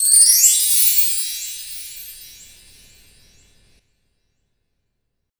Percs
PERC.88.NEPT.wav